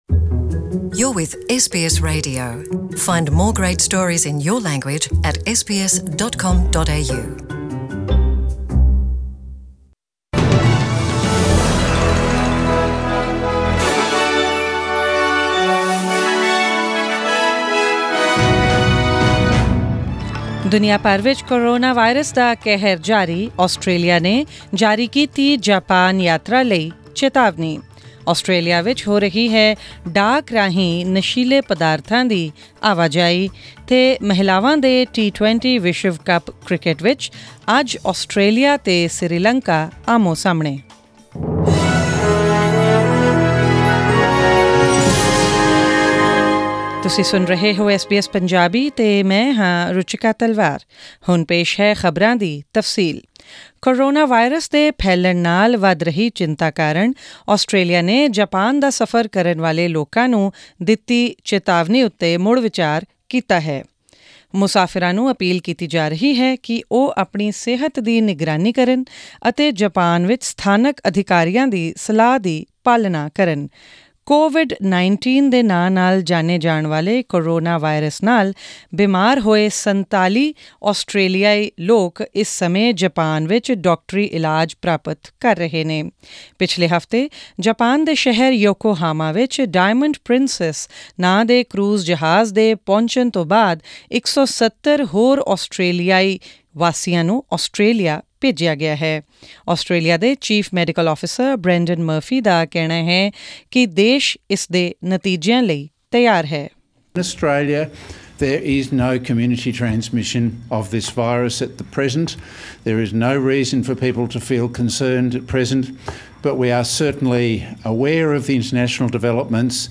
Presenting the major national and international news stories of today; including updates on sports, currency exchange rates and the weather forecast for tomorrow.